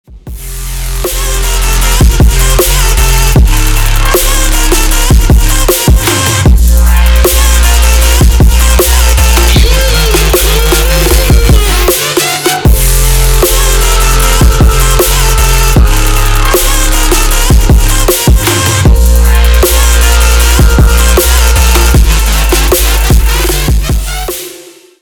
громкие
Electronic
Bass
Trapstep